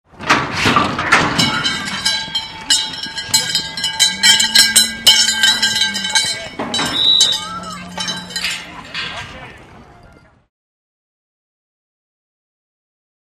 Rodeo Gate | Sneak On The Lot
Rodeo; Heavy Gate Open, Cowbell On Bronco, Heavy Grunts.